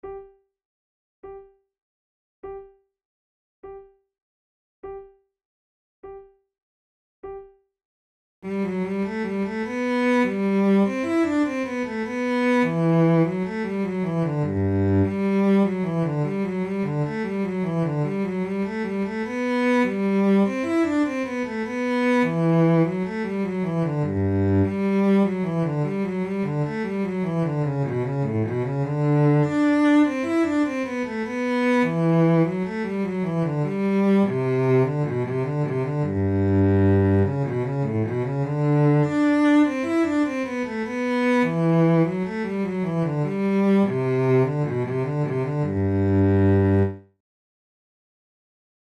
Sheet Music MIDI MP3 Accompaniment: MIDI
Categories: Baroque Gavottes Sonatas Written for Flute Difficulty: intermediate